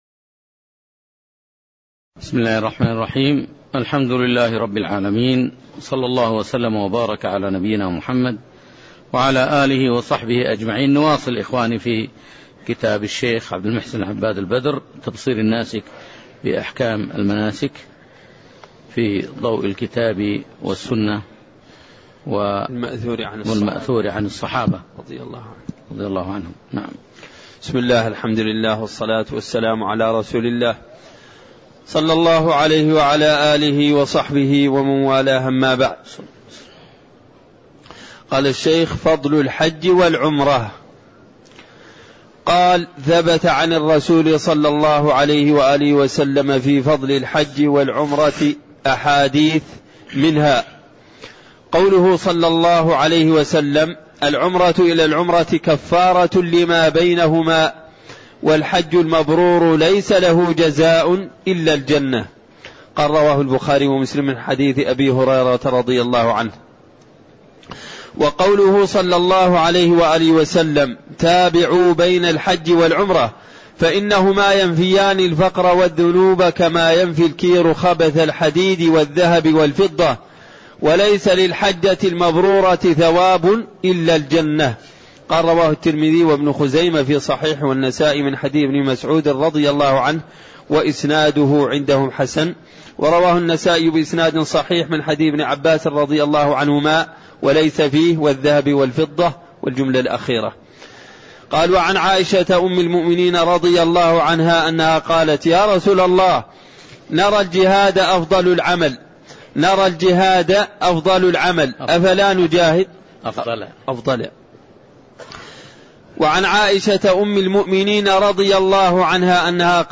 تاريخ النشر ٢٠ ذو القعدة ١٤٣٠ هـ المكان: المسجد النبوي الشيخ